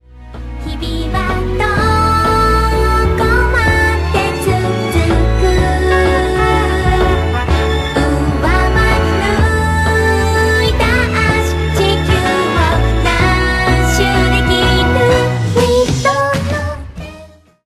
piano arr.